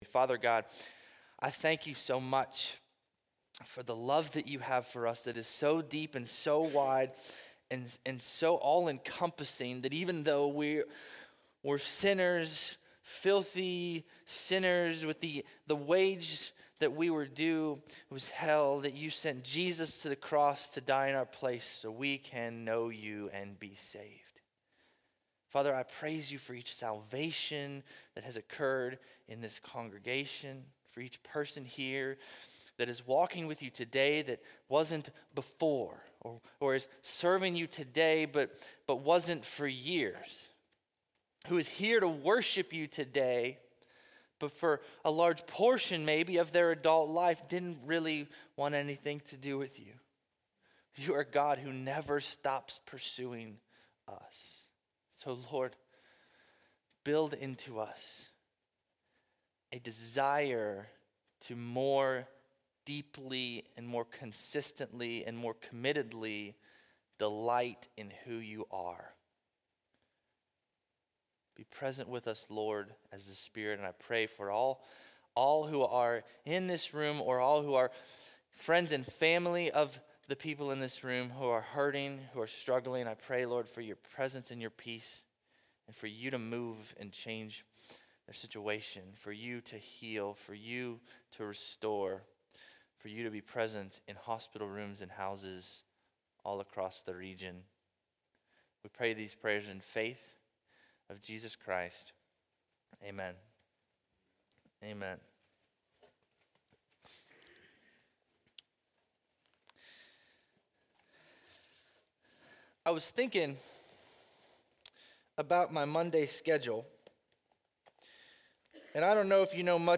Bible Text: Hebrews 10:19-25 | Preacher